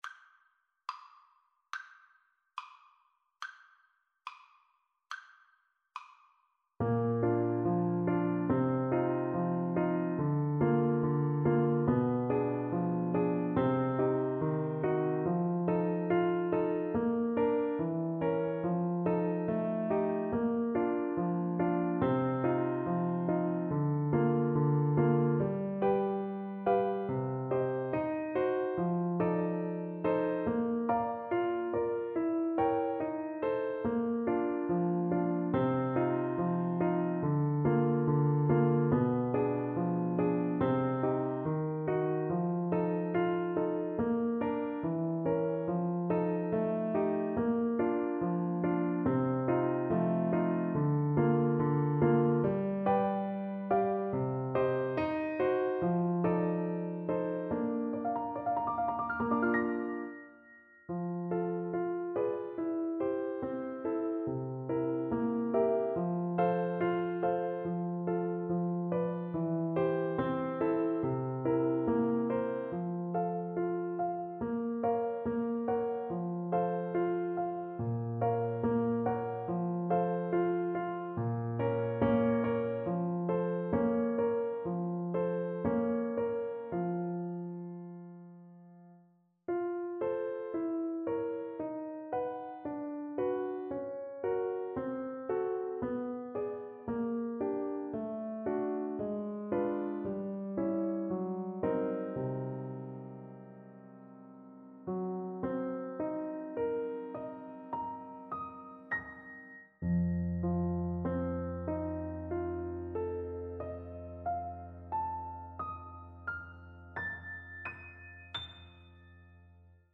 Play (or use space bar on your keyboard) Pause Music Playalong - Piano Accompaniment Playalong Band Accompaniment not yet available transpose reset tempo print settings full screen
Clarinet
Bb major (Sounding Pitch) C major (Clarinet in Bb) (View more Bb major Music for Clarinet )
Moderato assai. = 100 - 116 = 100
2/4 (View more 2/4 Music)
Classical (View more Classical Clarinet Music)